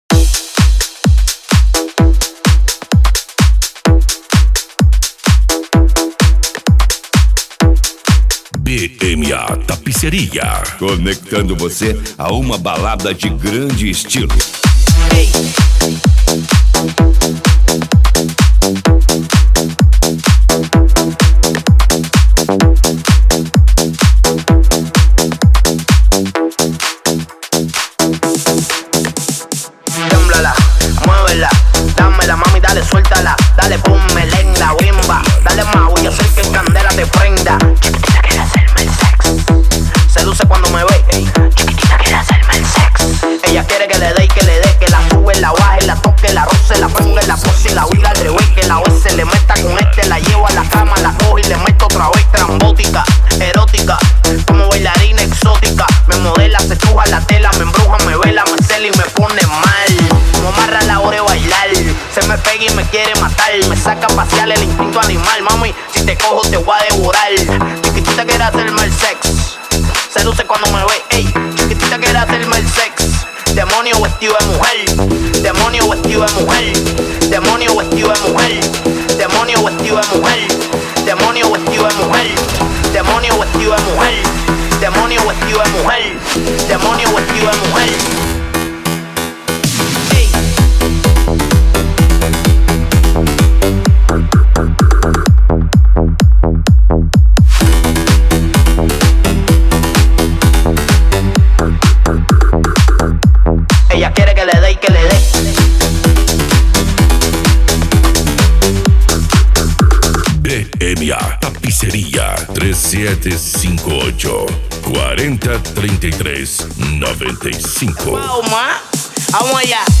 Eletronica
Musica Electronica
Psy Trance